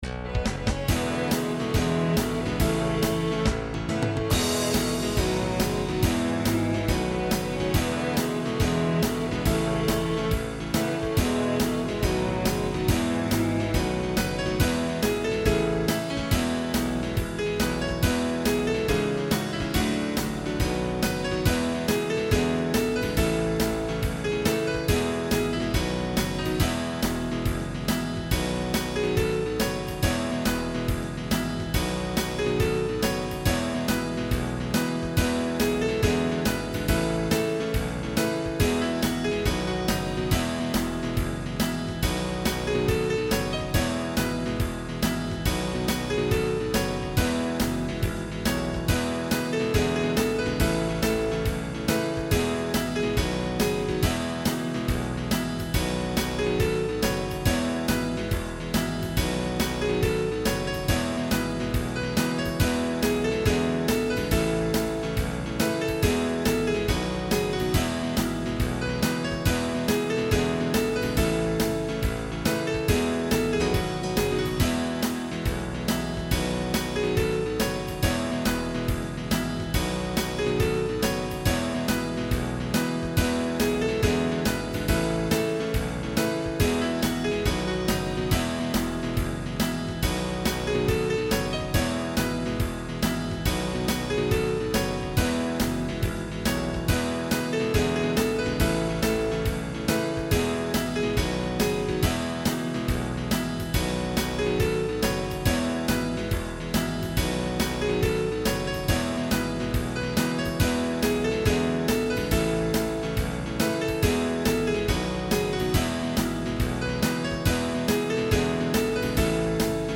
Recording from MIDI